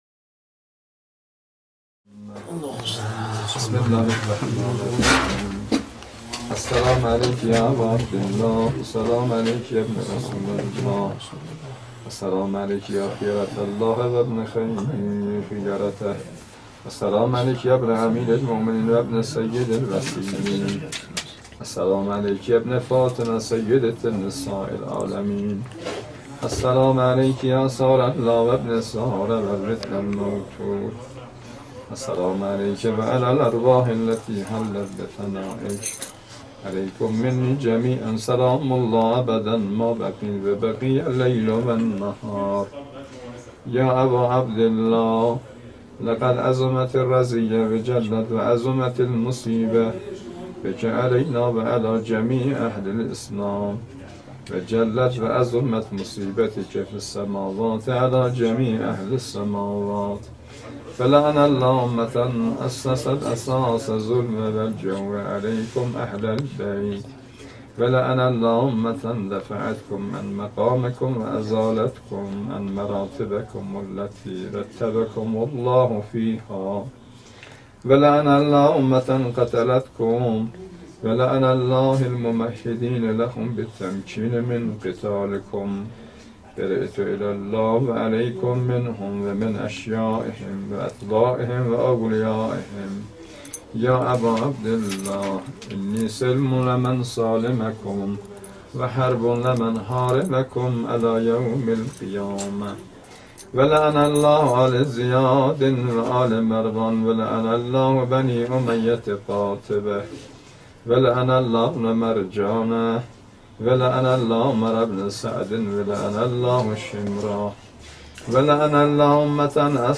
دانلود فایل صوتی زیارت عاشورا با نوای شهید حاج قاسم سلیمانی